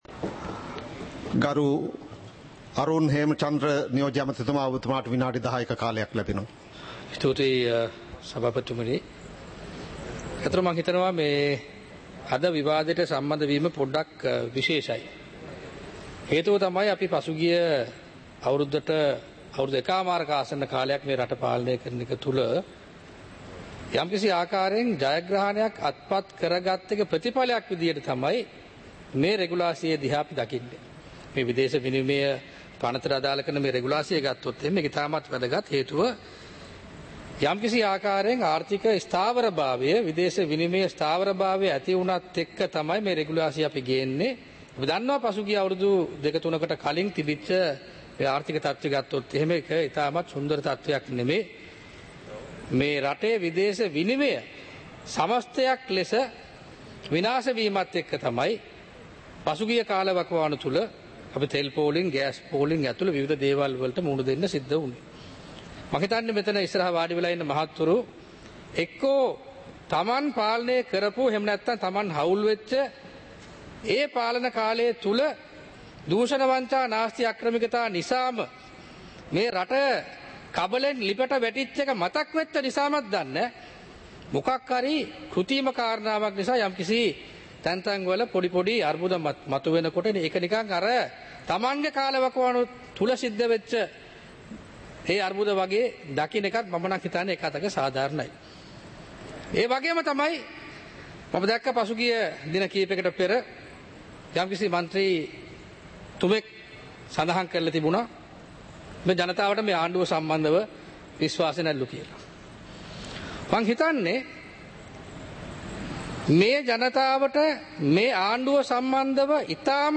சபை நடவடிக்கைமுறை (2026-03-03)